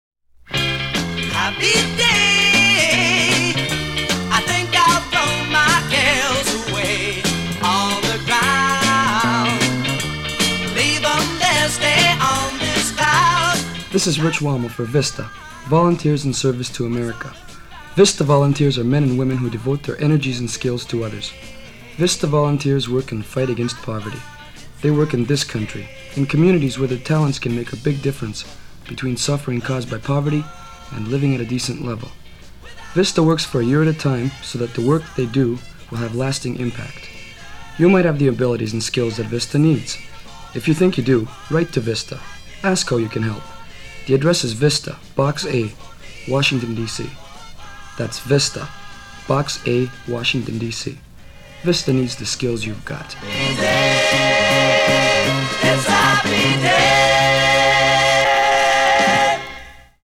(Public Service Announcements for